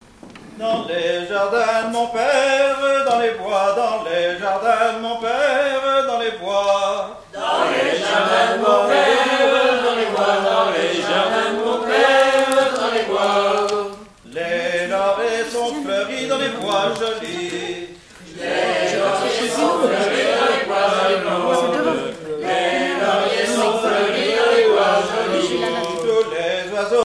Mémoires et Patrimoines vivants - RaddO est une base de données d'archives iconographiques et sonores.
7e festival du chant traditionnel : Collectif-veillée
Pièce musicale inédite